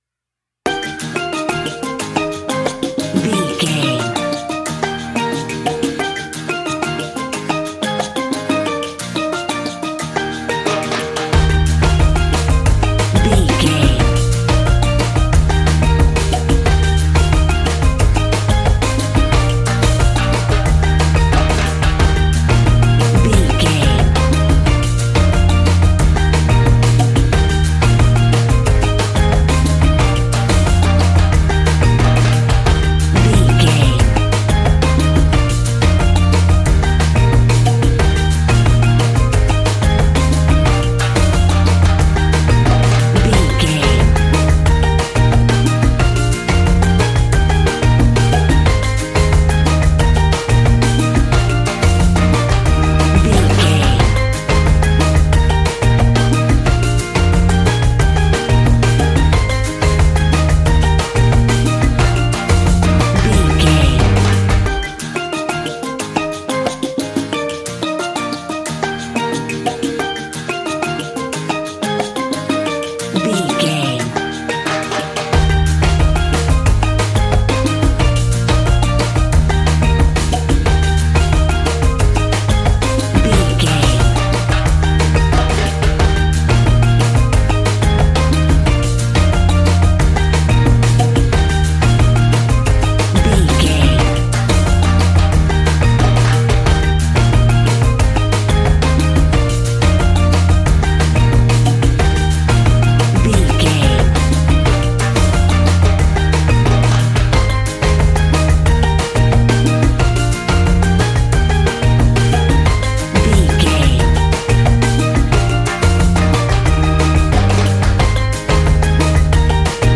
Uplifting
Ionian/Major
E♭
steelpan
happy
drums
percussion
bass
brass
guitar